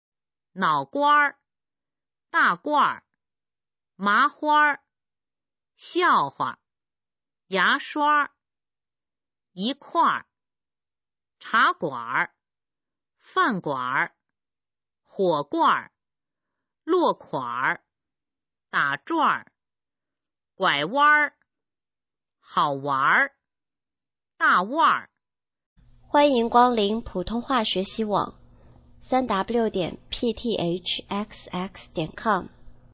普通话水平测试用儿化词语表示范读音第5部分